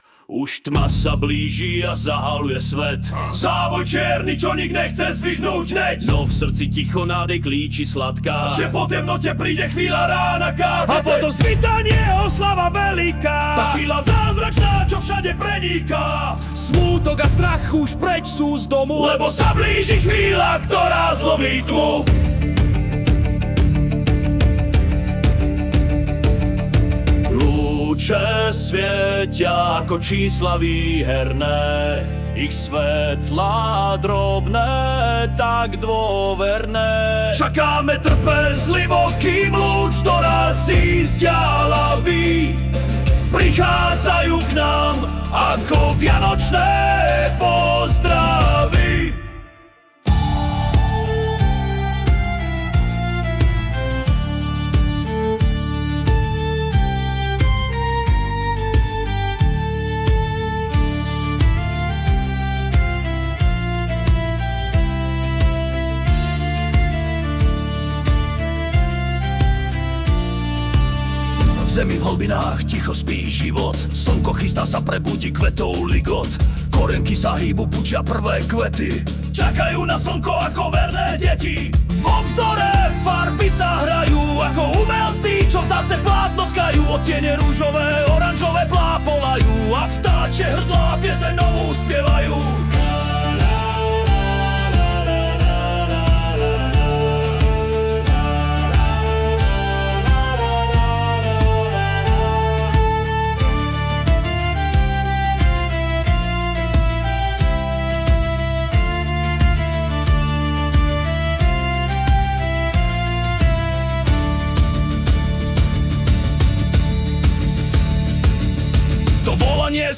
ten irský podkres mě zas uchvátil - díky